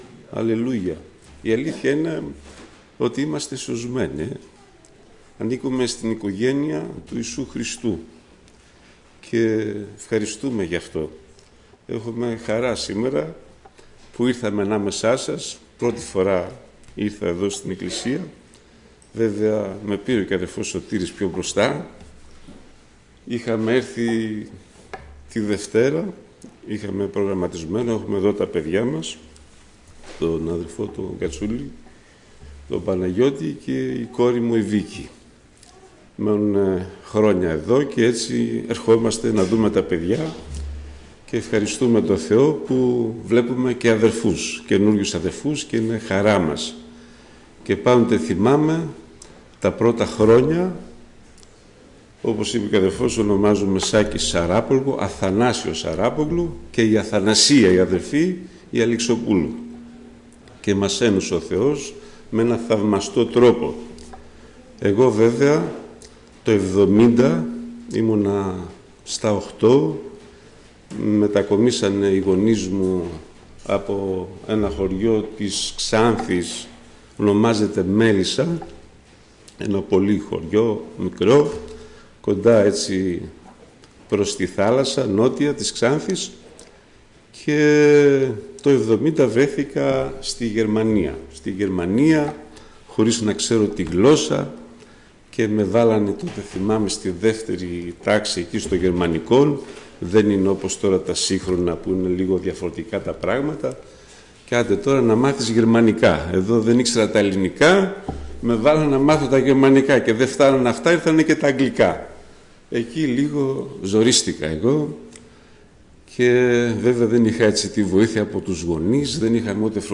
Ομιλητής: Διάφοροι Ομιλητές